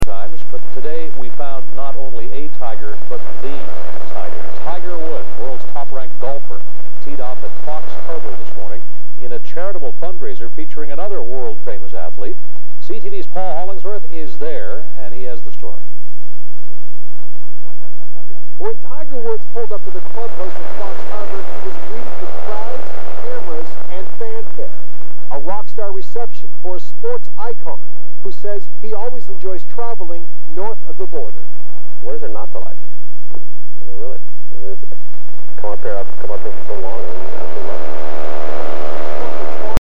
2120 - EE Nx, sounding like CBC would do